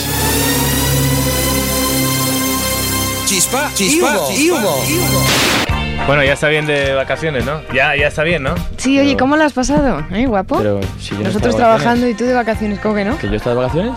Indicatiu del programa, comentari de les vacances.
Entreteniment